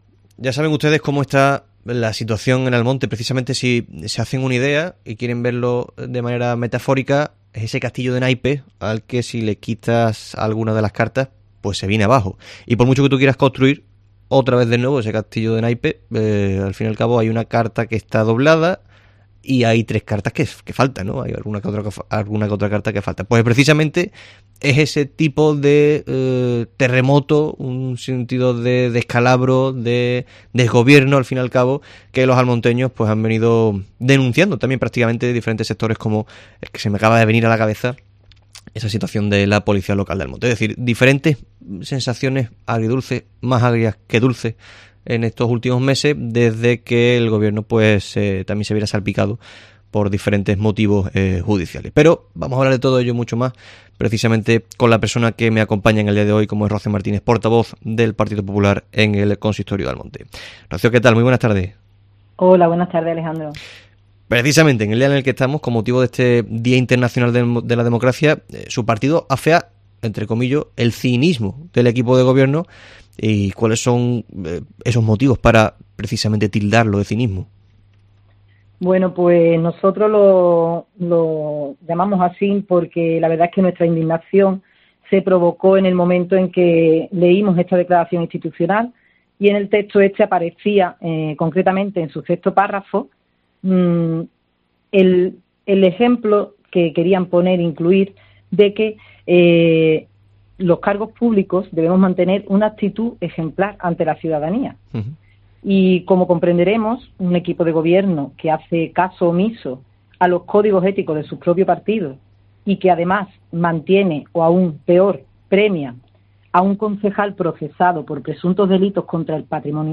Entrevista con Rocío Martínez, portavoz del Partido Popular en el Ayuntamiento de Almonte